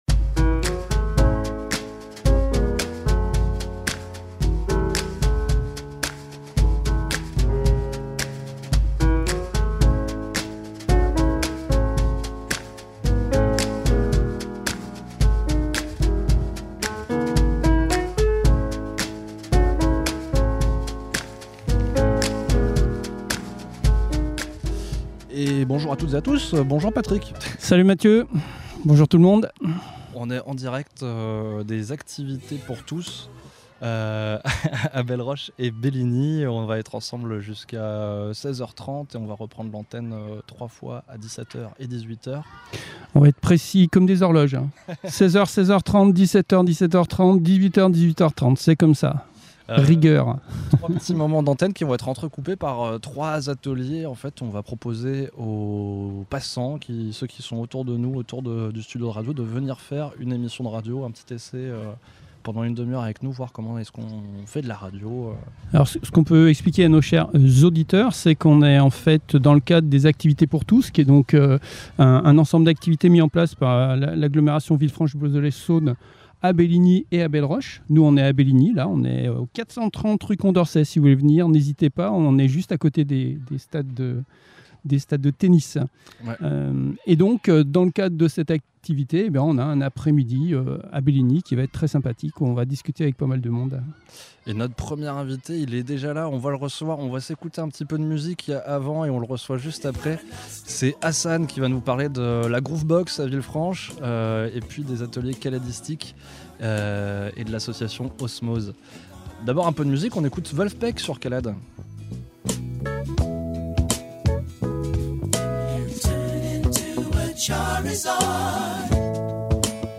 230724 Les Interviews